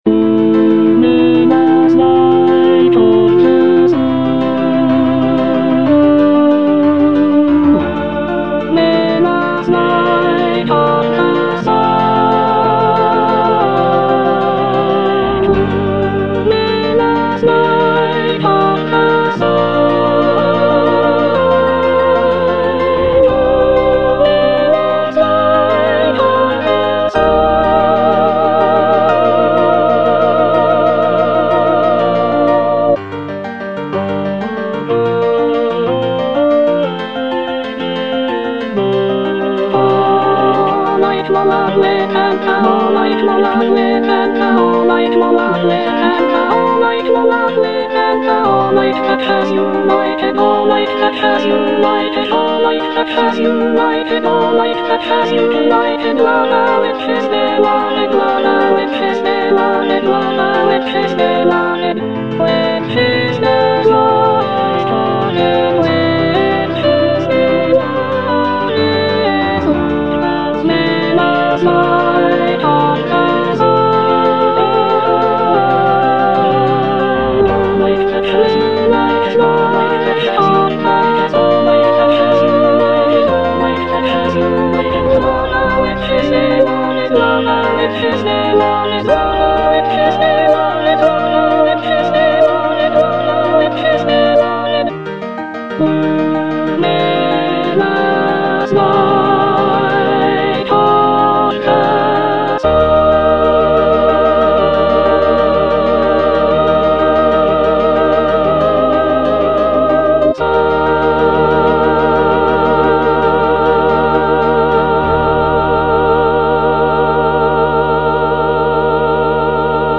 soprano II) (Emphasised voice and other voices
choral work